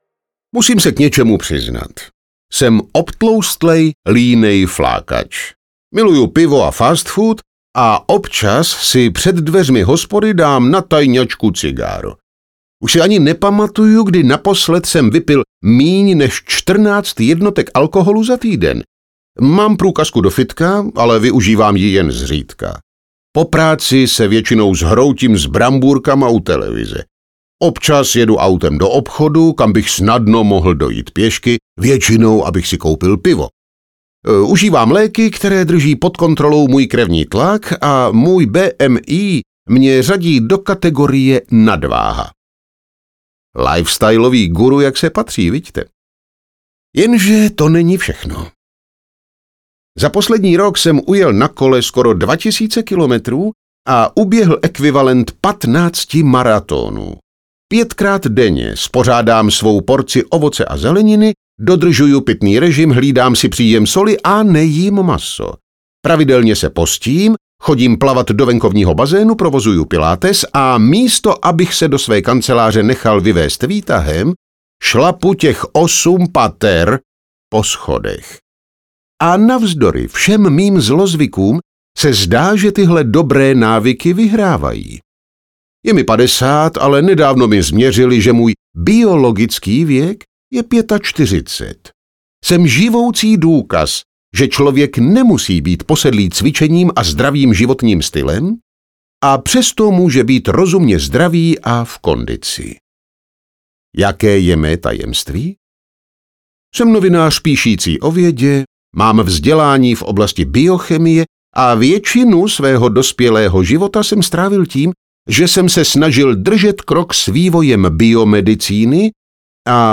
Audiokniha Tahle kniha vám možná zachrání život - Graham Lawton | ProgresGuru